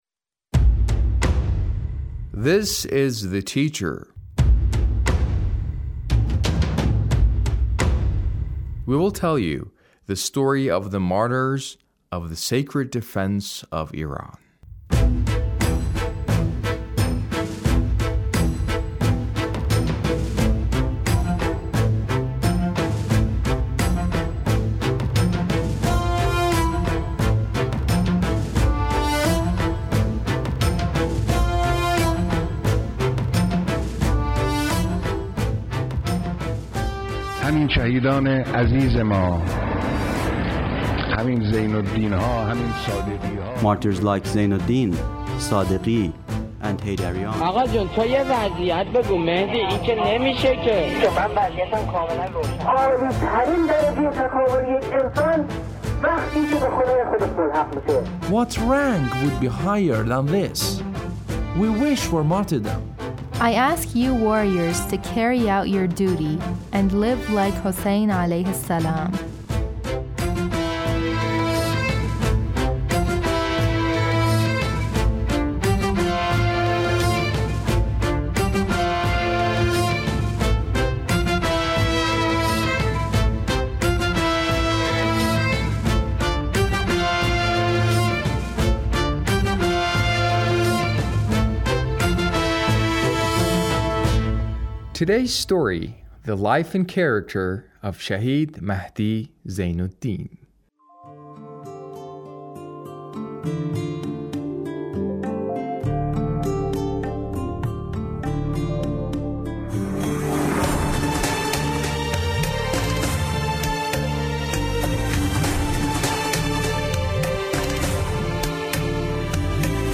A radio documentary on the life of Shahid Mahdi Zeinoddin- Part 3